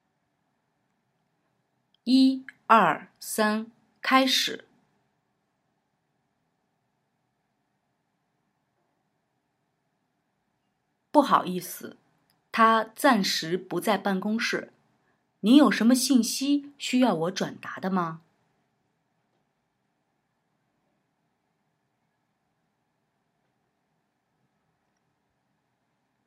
Höre dir Audio 3 an, in dem nur Part B eingesprochen wurde, und übernimm diesmal Part A! Damit du weißt, wann du einsetzen musst, gibt es vorab ein Startsignal.
Übung 3: Sprich Part A!